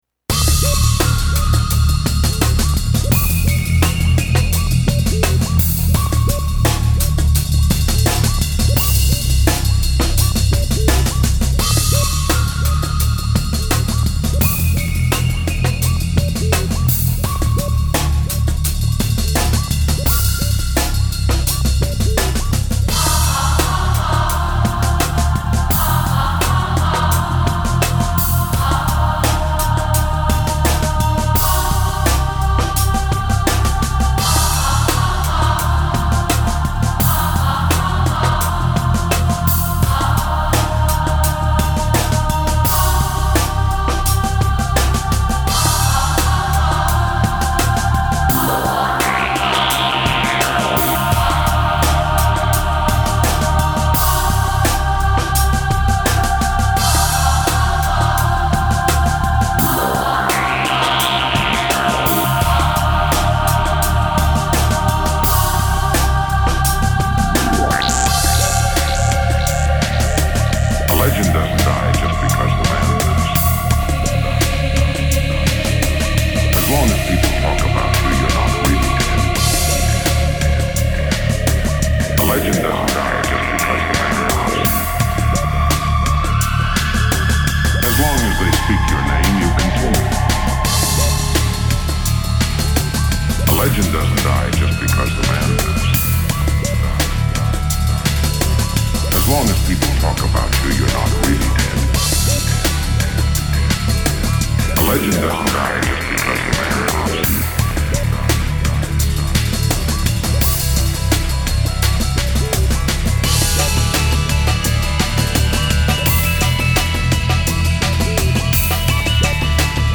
Sci Fi Breakbeat / Industrial / Noise / Experimental/ Breaks